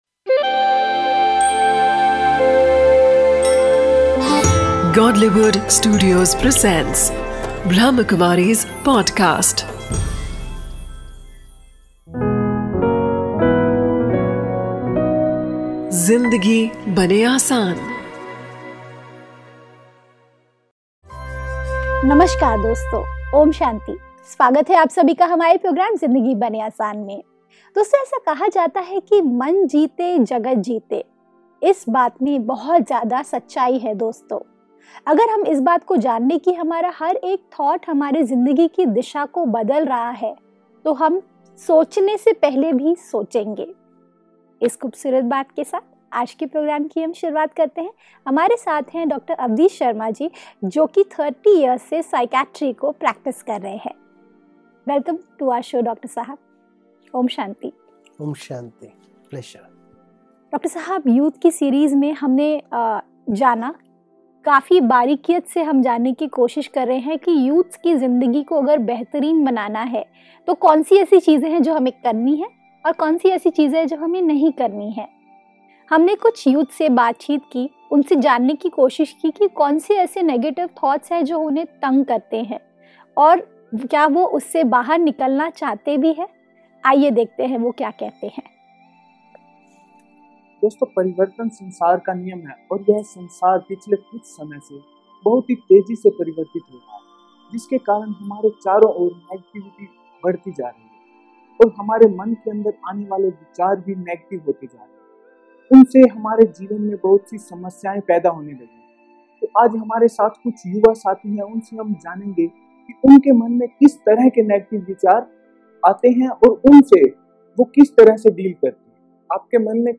a collection of invaluable speeches